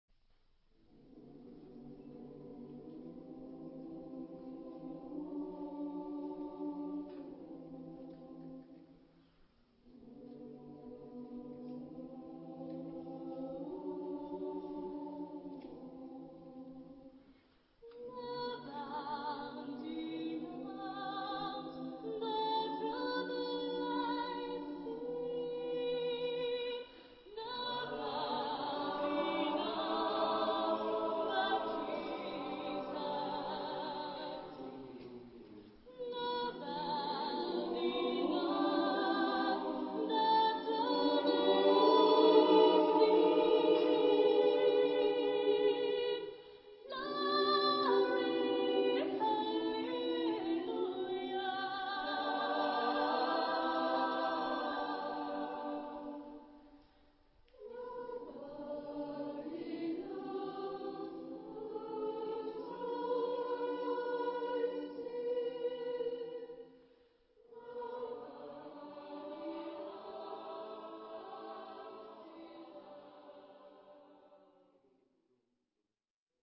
SATB (4 voices mixed) ; Full score.
Sacred. Spiritual.
sung by Chorilla, Choeur de l'Ill et de la Largue